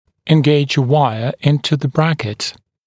[ɪn’geɪʤ ə ‘waɪə ‘ɪntə ðə ‘brækɪt][ин’гейдж э ‘уайэ ‘интэ зэ ‘брэкит]ввести дугу в паз брекета